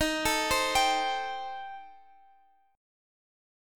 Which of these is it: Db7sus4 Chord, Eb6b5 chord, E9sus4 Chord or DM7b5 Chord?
Eb6b5 chord